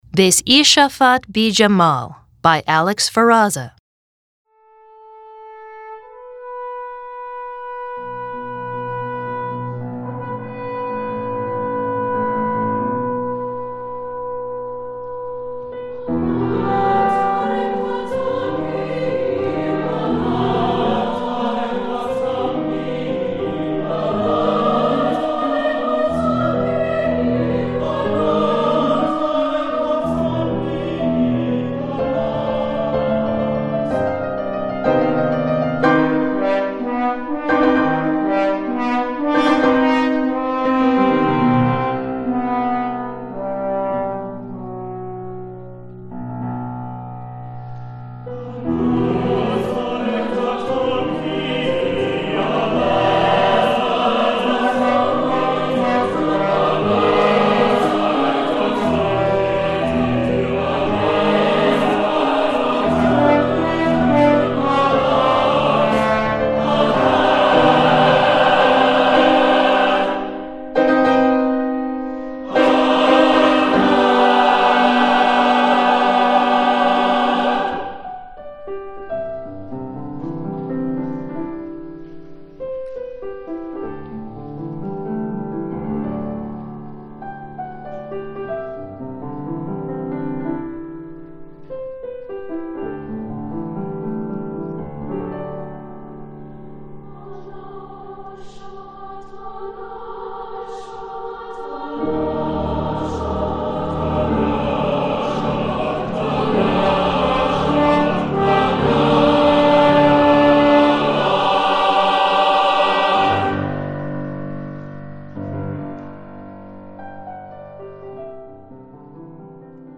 Choral Multicultural
mixed chorus divisi with piano and optional horn
SATB Divisi